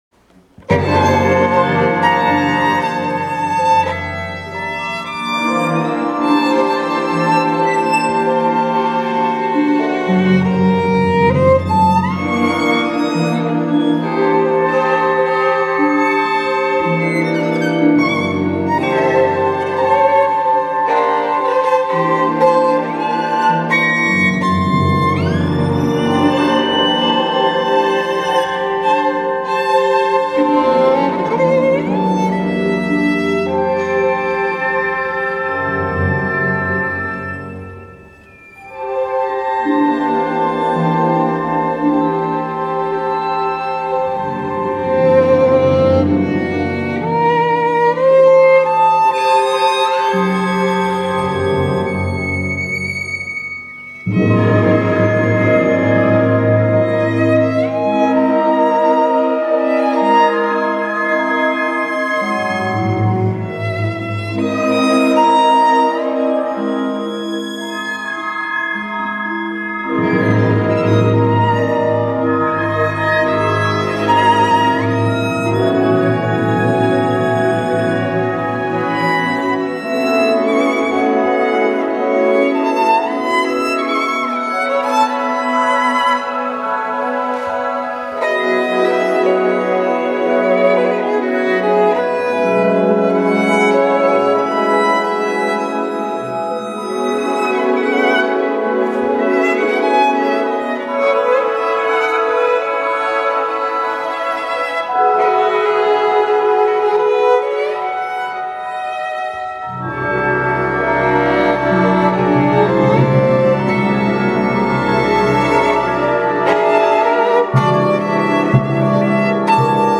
Concerto pour violon et orchestre
violon solo